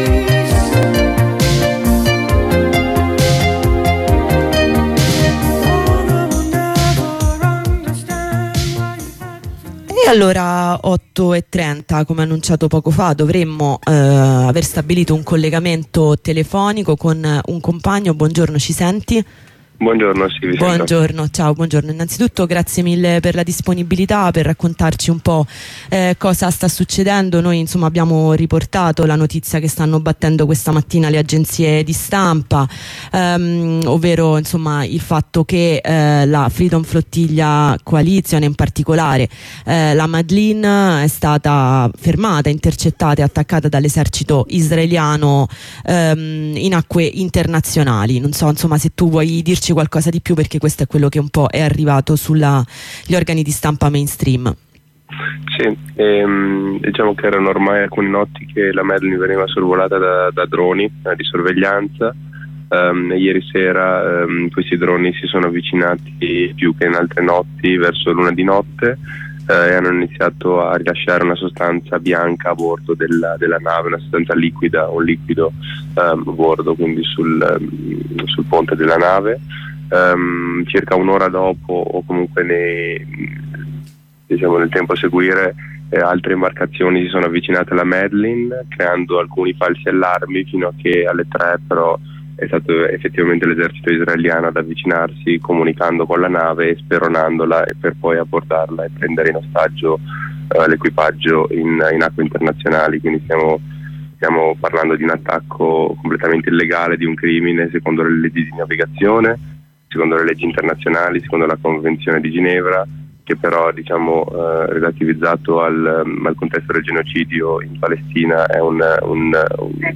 Corrispondenza dall'Italia con un compagno di Freedom Flotilla dopo che nella notte sono state interrotte le comunicazioni con la nave Madleen che stava portando aiuti a Gaza mentre navigava in acque internazionali. Dalle notizie ricevute, sappiamo che l'equipaggio è stato tradotto in Israele e siamo in attesa di ulteriori dettagli.